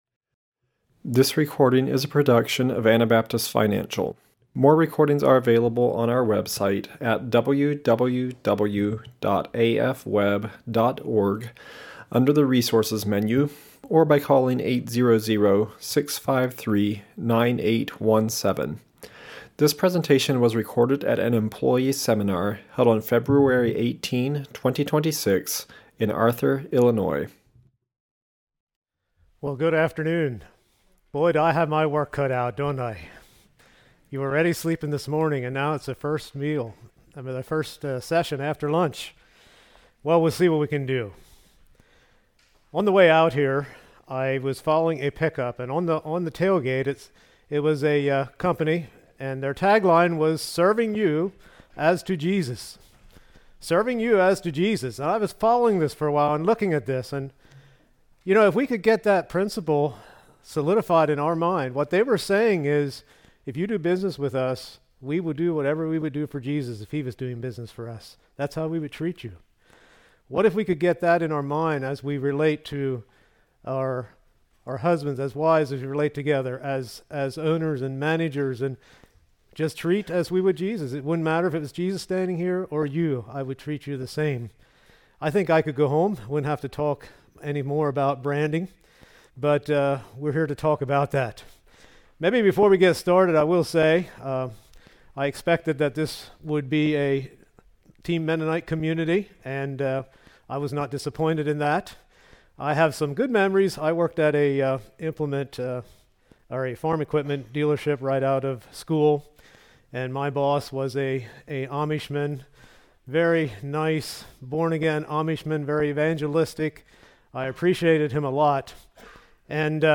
Illinois Employee Seminar 2026